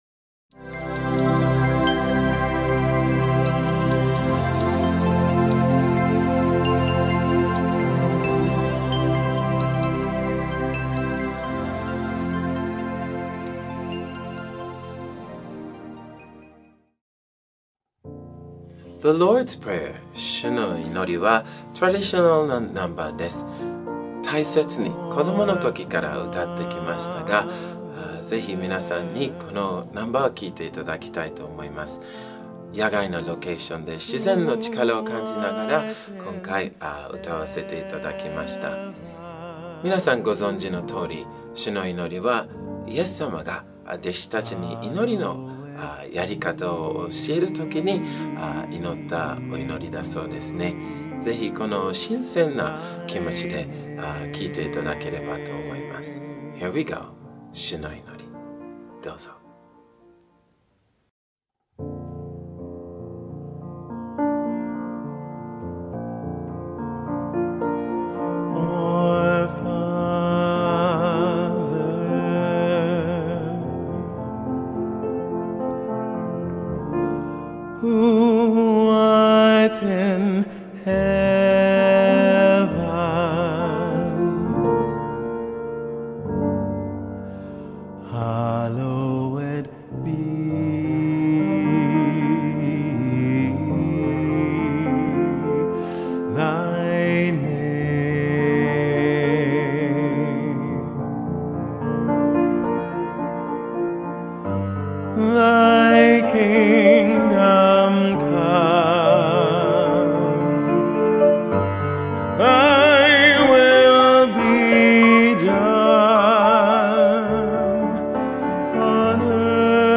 自然の中でのアメイジングな撮影の末完成した感動的動画です。